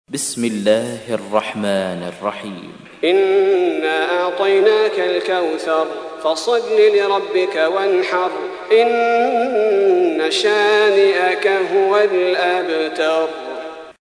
تحميل : 108. سورة الكوثر / القارئ صلاح البدير / القرآن الكريم / موقع يا حسين